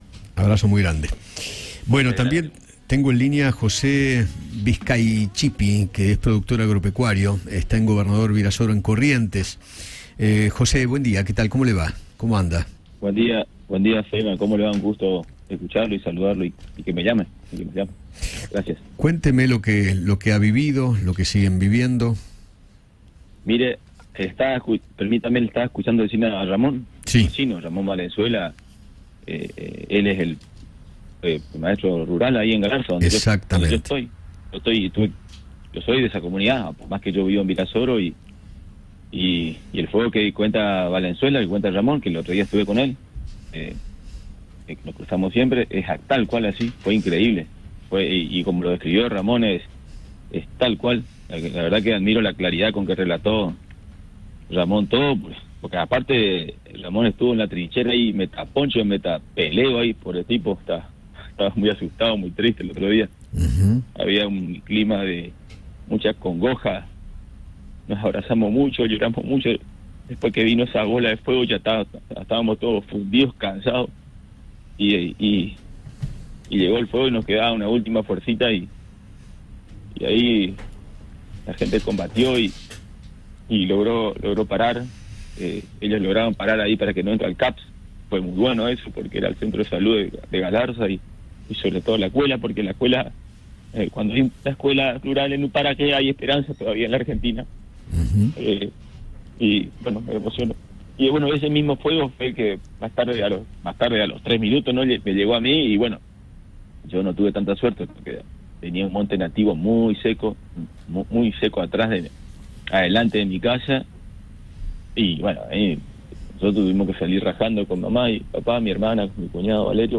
La emoción de un productor agropecuario tras las donaciones que están llegando a Corrientes - Eduardo Feinmann